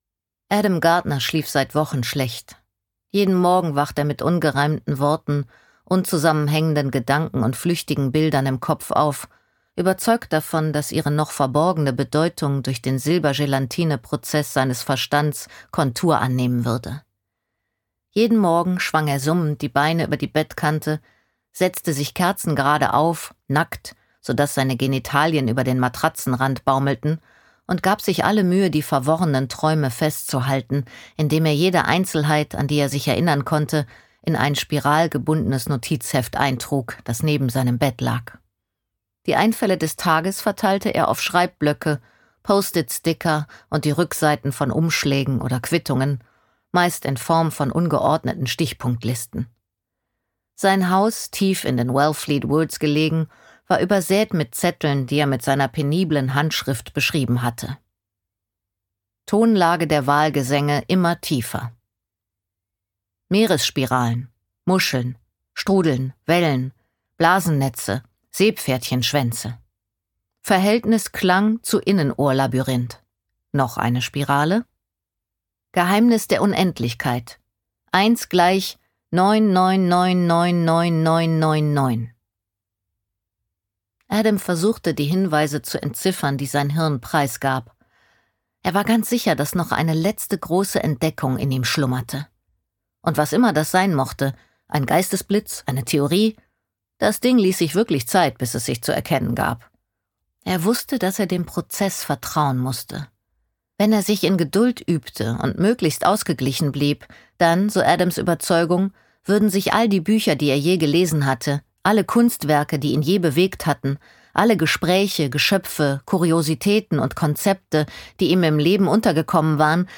Dieses Hörbuch ist ursprünglich unter dem Titel Treibgut erschienen.